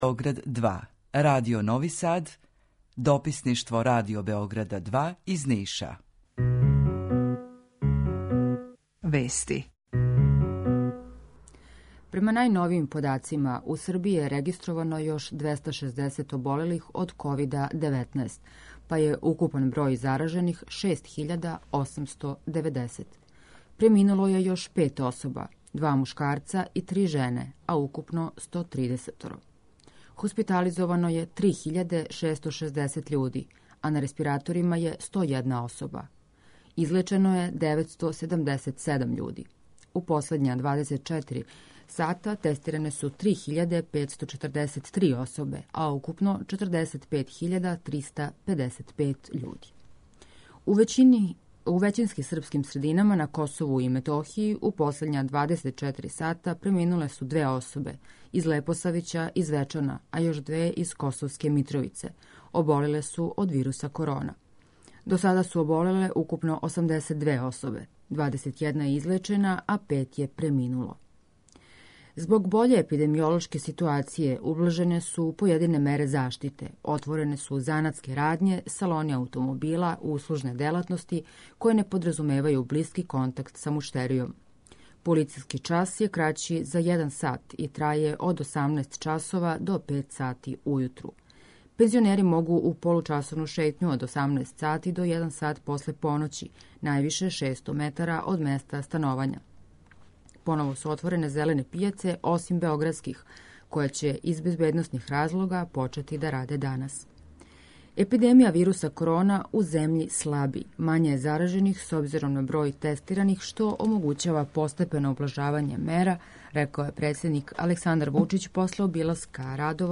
Укључење Радио Грачанице
У два сата, ту је и добра музика, другачија у односу на остале радио-станице.